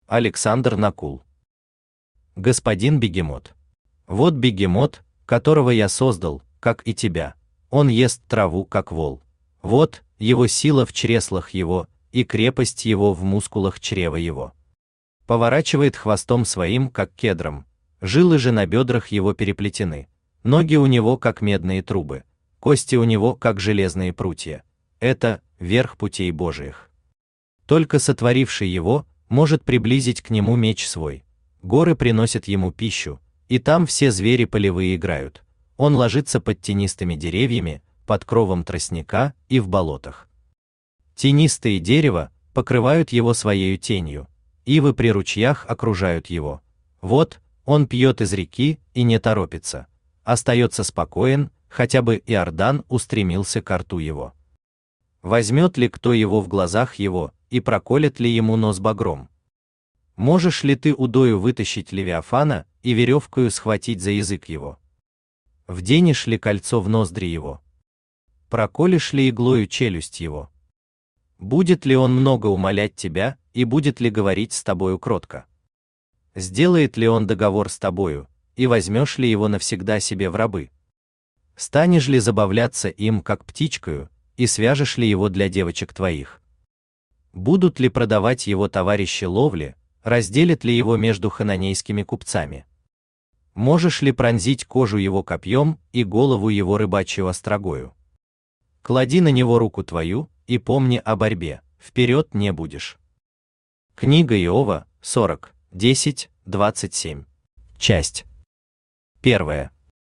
Аудиокнига Господин Бегемот | Библиотека аудиокниг
Aудиокнига Господин Бегемот Автор Александр Накул Читает аудиокнигу Авточтец ЛитРес.